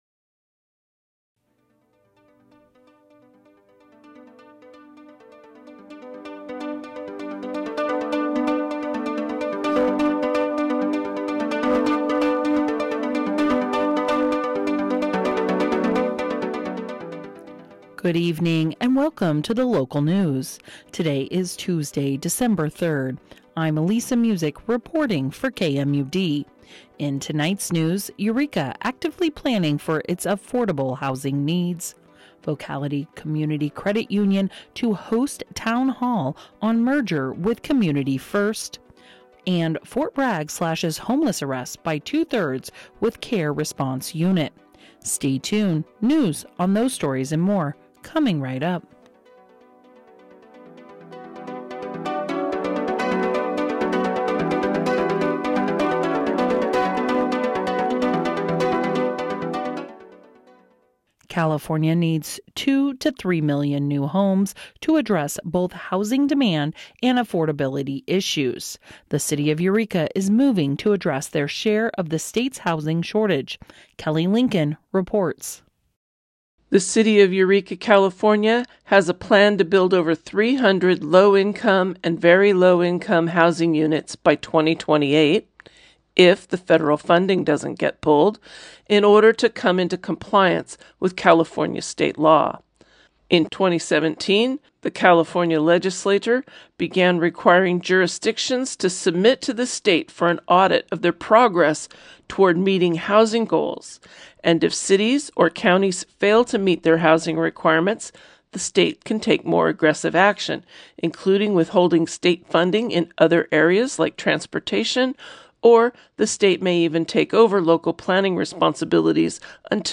KMUD News Broadcast for 12/03/24